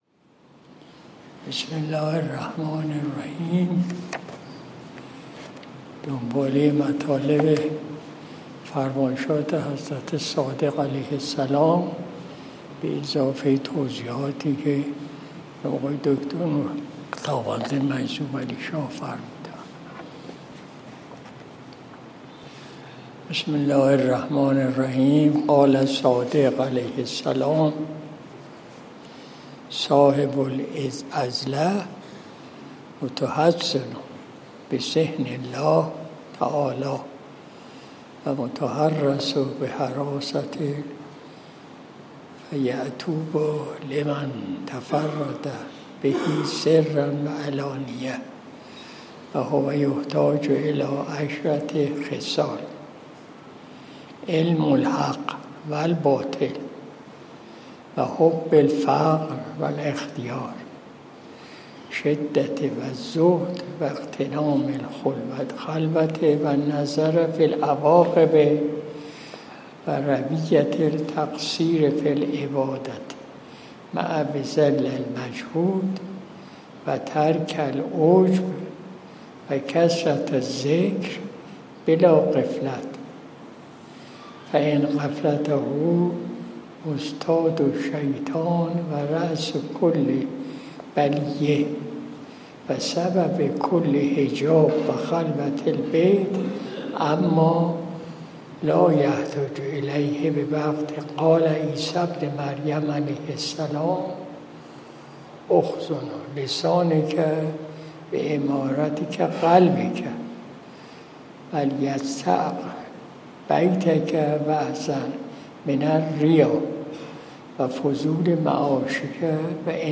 مجلس شب دوشنبه ۱۱ تیر ماه ۱۴۰۲ شمسی